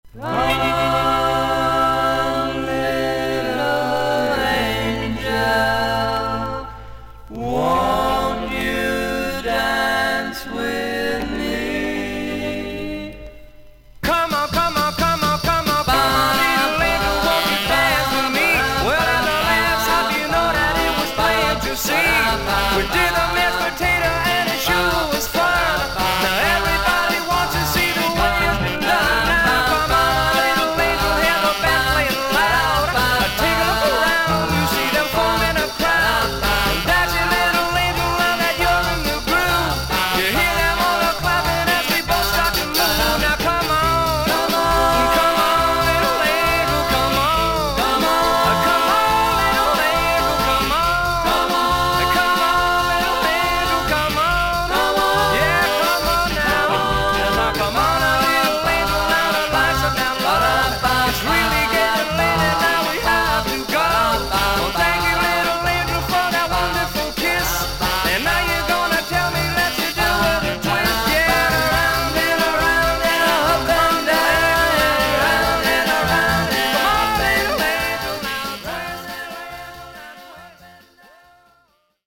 ホワイト・ドゥー・ワップ。
VG++〜VG+ 少々軽いパチノイズの箇所あり。クリアな音です。